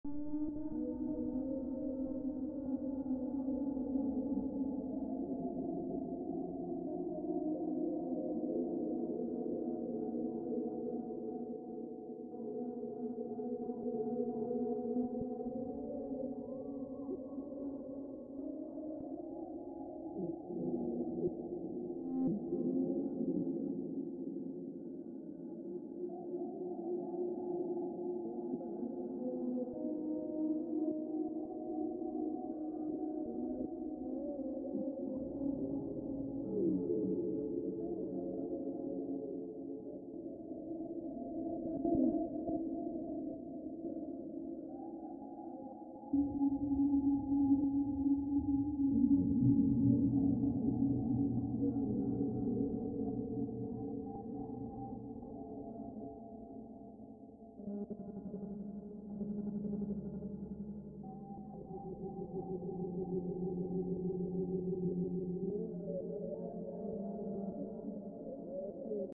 creepy-ambience-53988.mp3